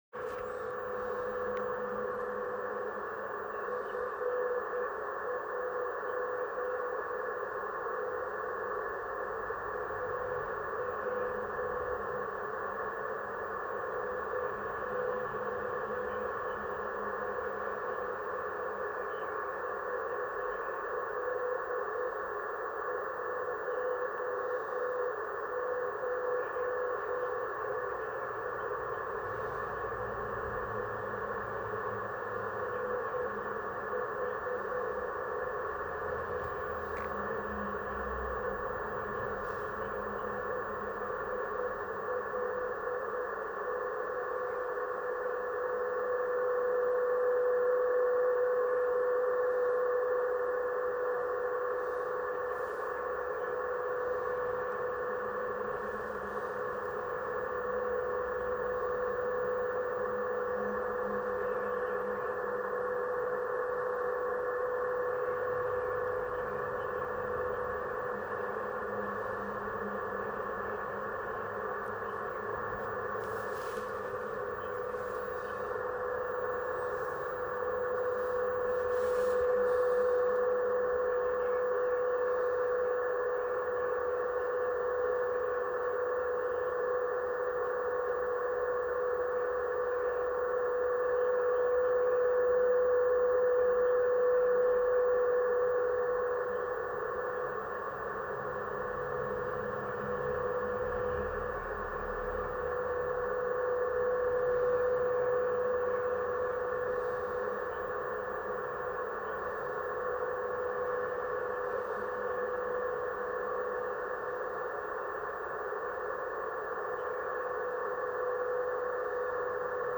1er report entre 2 « Slotted antennas »